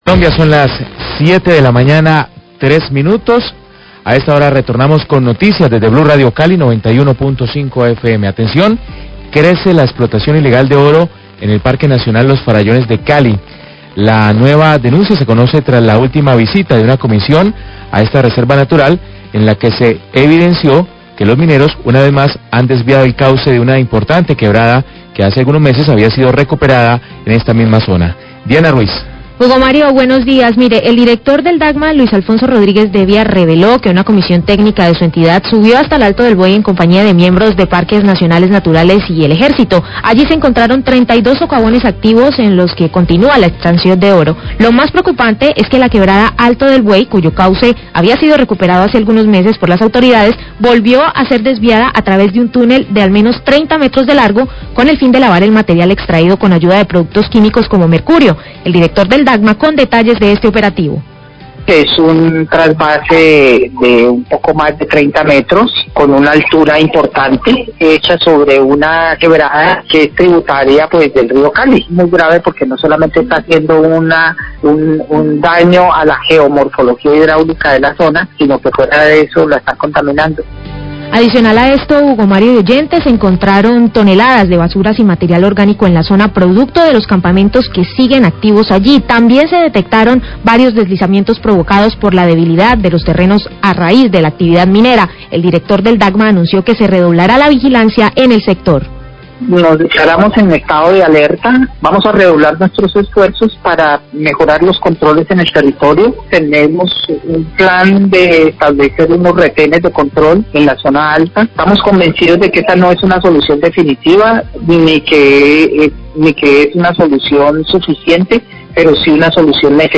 Radio
Tras la última visita técnica a la Parque Nacional Los Farallones, el director del Dagma, Luis Alfonso Rodríguez, denunció que se continúa la explotación minera ilegal en dicho sector, incluyendo la intervención de afluentes que ya habían sido recuperados. El funcionario da detalles del operativo realizado en conjunto con el Ejército Nacional.